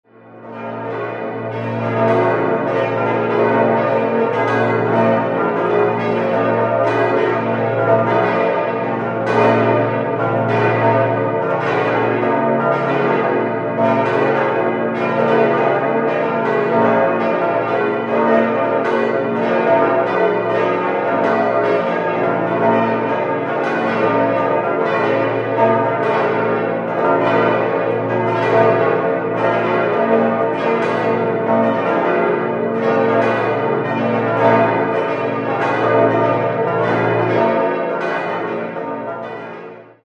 Die sieben Glocken wurden 1948 beim Bochumer Verein für Gussstahlfabrikation in V12-Rippe gegossen als Ersatz für das im Jahr zuvor hergestellte, ursprünglich 8-stimmige Geläute (a°-c'-d'-e'-f'-g'-a'-c'') in Sekundschlagrippe, welches aber wegen mangelhafter Klangqualität und fehlerhafter Schlagtonlinie abgelehnt wurde. Ein eindrucksvolles Geläute in einer seltenen Disposition mit Halbtonschritt, wodurch praktisch alle wichtigen Tonmotive enthalten sind. Die große Glocke entwickelt trotz massiver Kröpfung einen ansprechenden Klang.
Unter den tontiefsten Geläuten im Bistum Eichstätt nimmt dieses den dritten Platz nach dem Eichstätter Dom und der Klosterkirche Plankstetten ein. Das Geläut hängt auf insgesamt vier Etagen im Turm. Alle Glocken läuten an gekröpften Jochen.